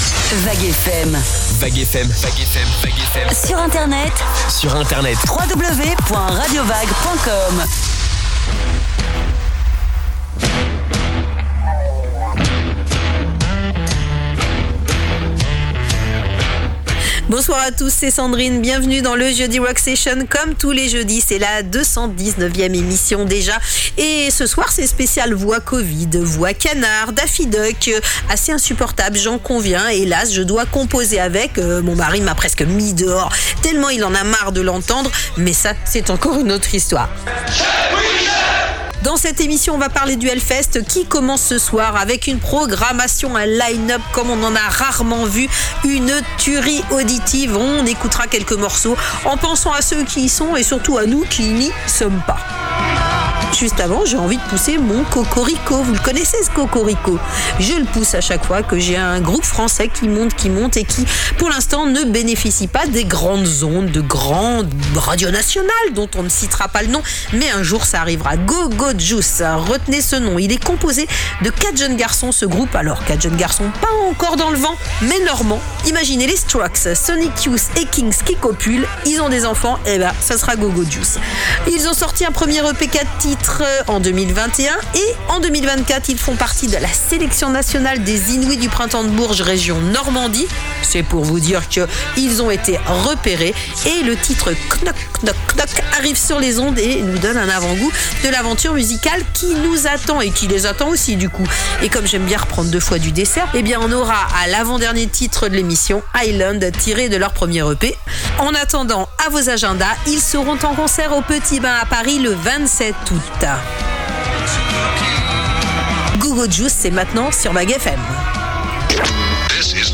Ce jeudi, l'émission était en partie concentrée sur la programmation du Hellfest 2024, mais j'étais en pic de Covid donc pas facile de se concentrer, de parler avec un masque et d'avoir les bons mots quand on a passé plusieurs nuits blanches. Vous m'excuserez mes petits écarts de langage ou mon anglais toujours aussi "frenchy" sans effort...